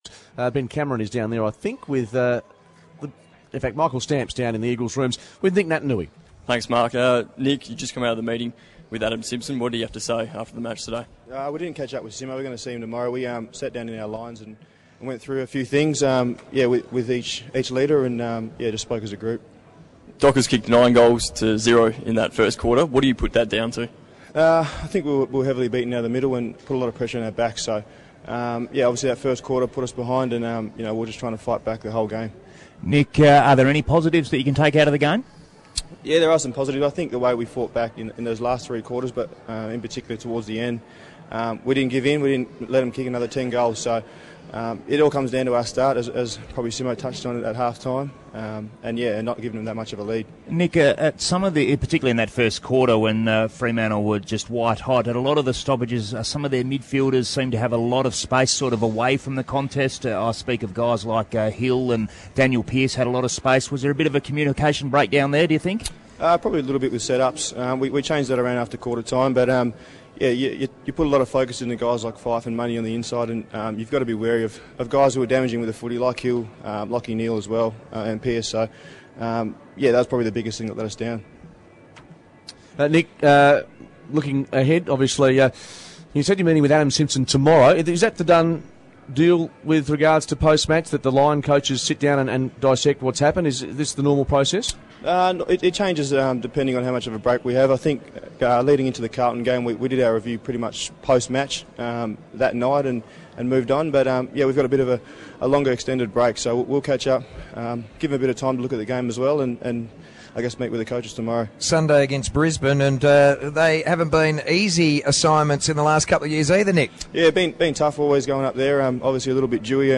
Nic Natanui speaks after the West Coast Eagles loss to Fremantle